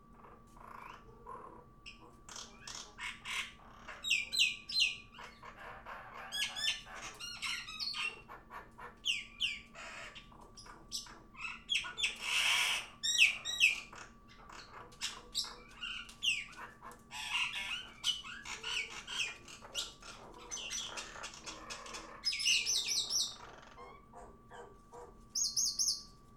Common myna singing with normal sleep.
commonmynasong.mp3